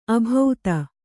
♪ abhauta